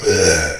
spawners_mobs_uruk_hai_neutral.3.ogg